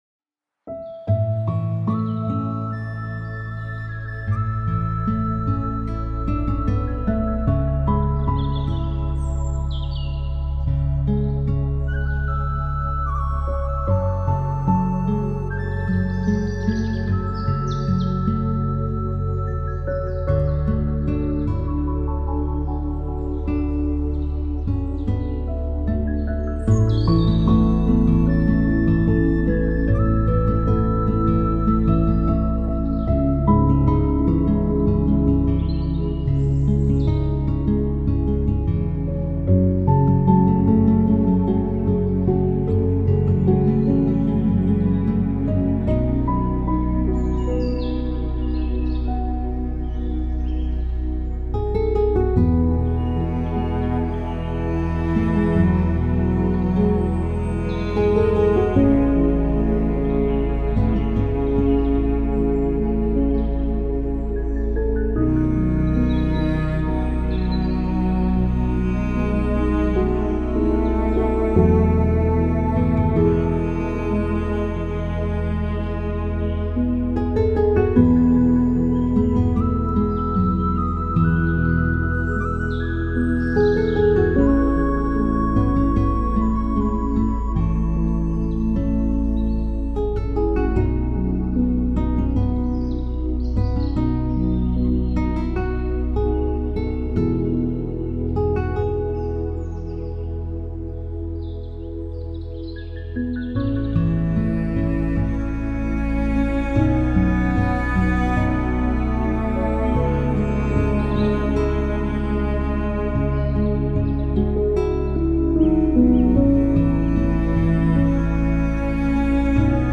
soft ambient audio